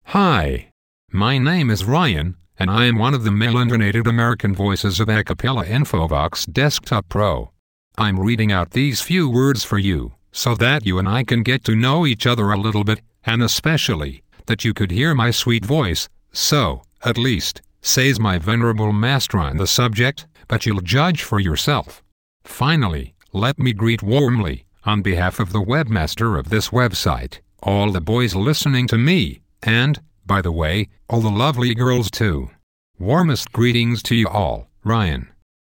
Texte de démonstration lu par Ryan, voix masculine américaine d'Acapela Infovox Desktop Pro
Écouter la démonstration de Ryan, voix masculine américaine d'Acapela Infovox Desktop Pro